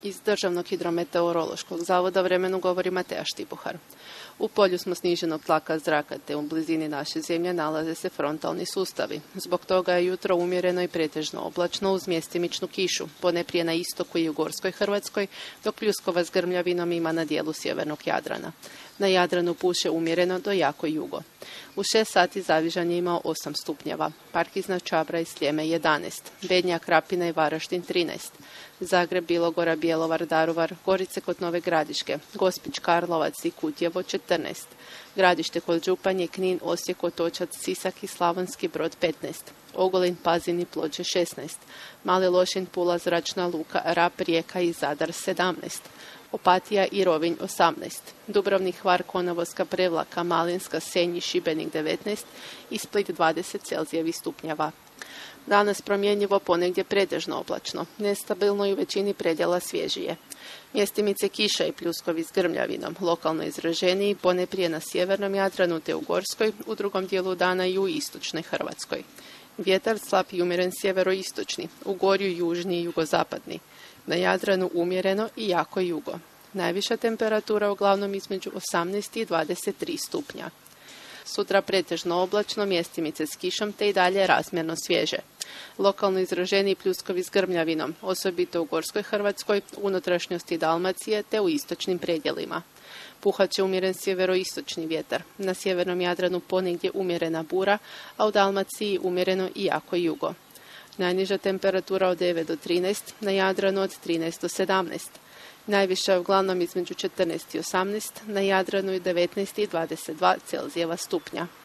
Vremenska prognoza
Vremensku prognozu tri puta na dan za naše informativne emisije donose prognostičari Državnog hidrometeorološkog zavoda. Prognoziraju vrijeme u Hrvatskoj u nastavku dana i idućih dana te upozoravaju na opasne vremenske pojave.